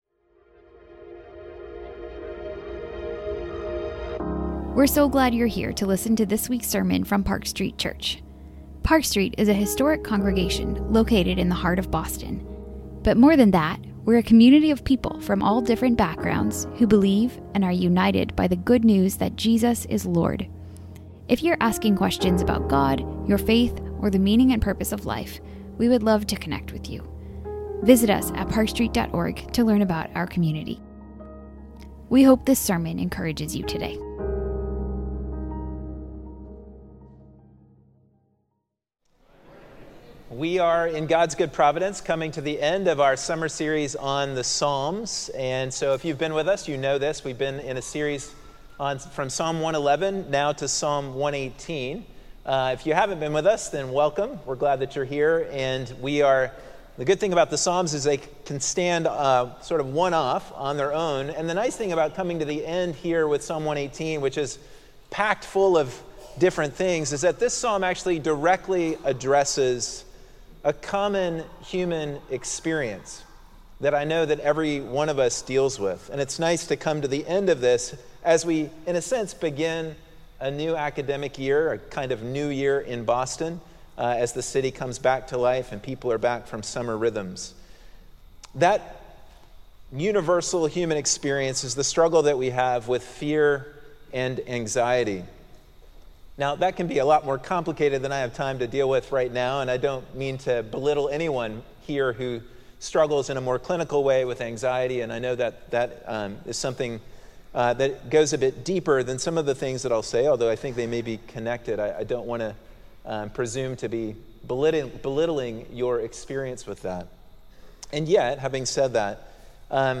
A message from the series "The Psalms."